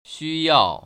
[xūyào] 쉬야오  ▶